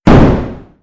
scsm_explosion2w.wav